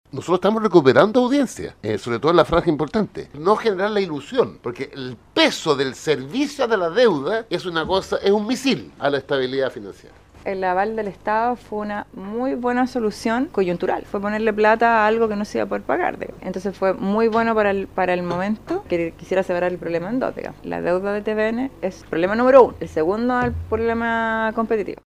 Sin embargo, al ser cuestionados al término de la sesión, el presidente del directorio del canal estatal, Francisco Vidal, señaló que “el peso del servicio de la deuda es un misil a la estabilidad financiera” de la estación.